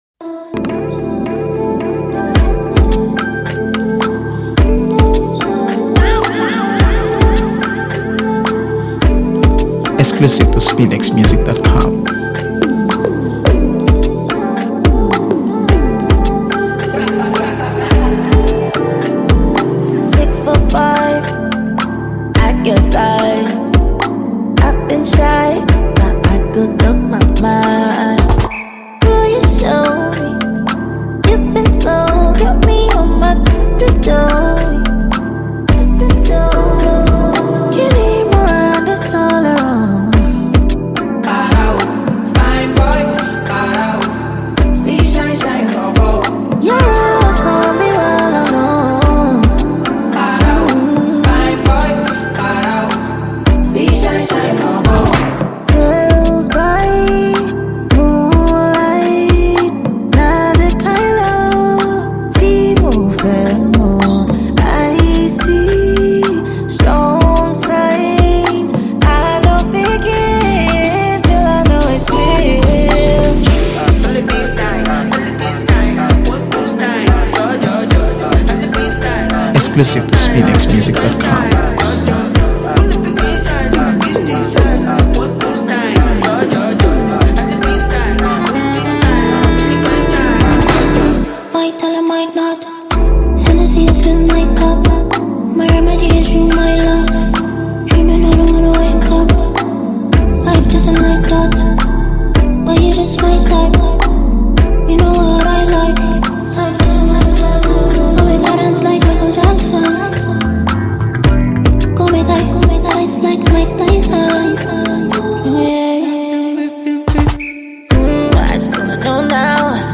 AfroBeats | AfroBeats songs
enchanting vocals
a seamless blend of Afrobeat rhythms and soulful melodies